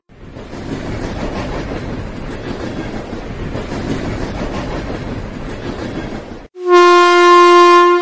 train.mp3